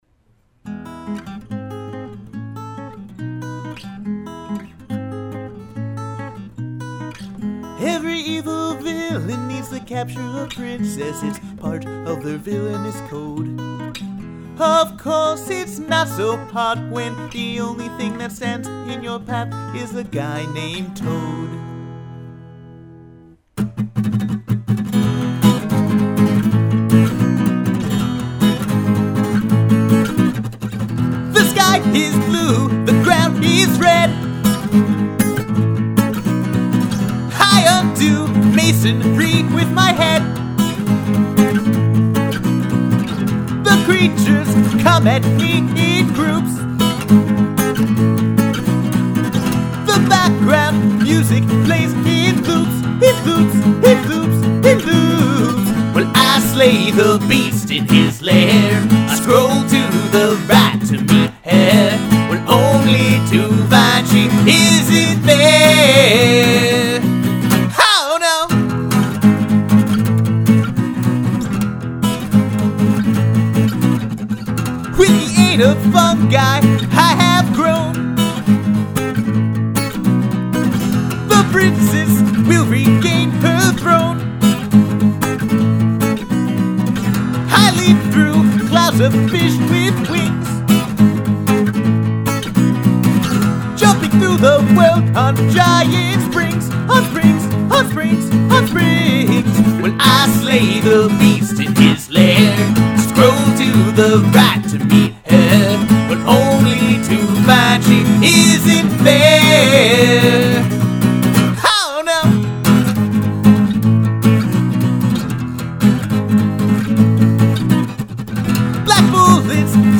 Quality: Good